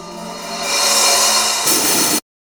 0504L OPN HH.wav